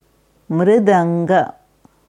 Es wird in der indischen Devanagari Schrift geschrieben मृदङ्ग, in der IAST Transliteration mit diakritischen Zeichen mṛd-aṅga. Hier hörst du, wie eine Sanskrit Expertin Mridanga ausspricht.